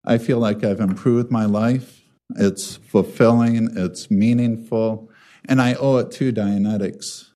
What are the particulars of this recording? AUDIO: Scientology debate breaks out at Portage City Council meeting